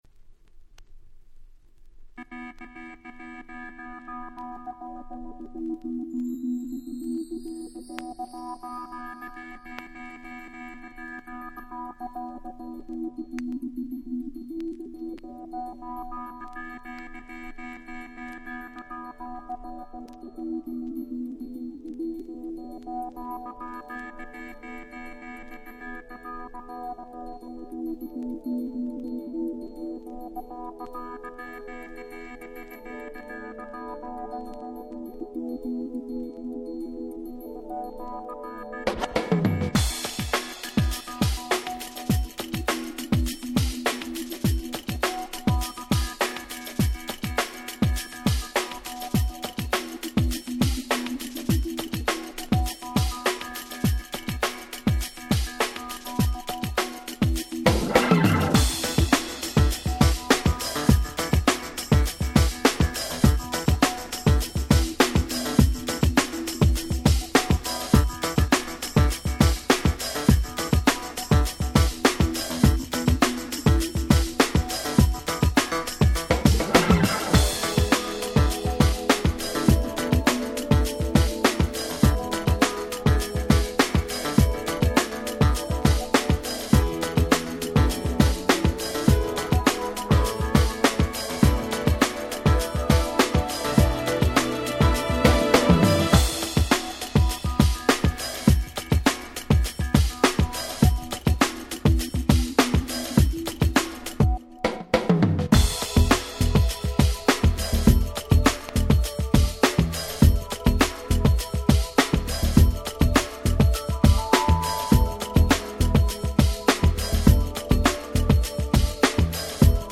93' Nice UK R&B !!
キャッチーなサビが印象的な明るい1曲！